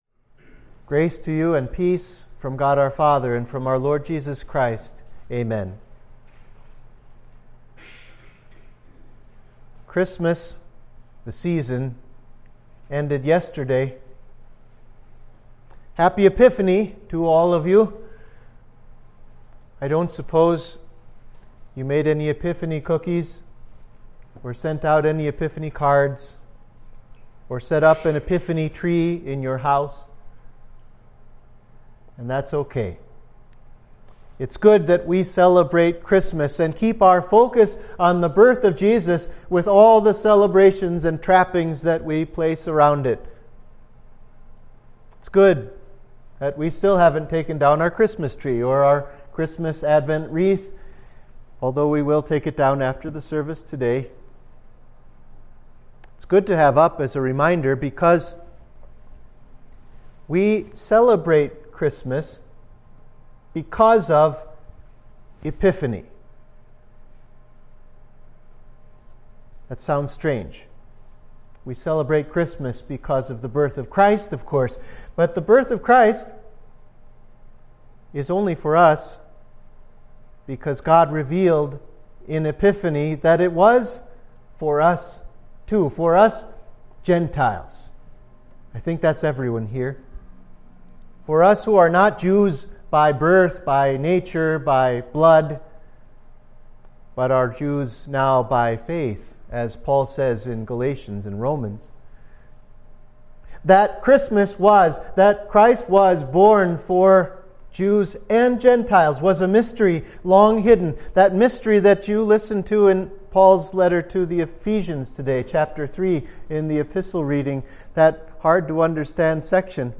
Sermon for the Epiphany of Our Lord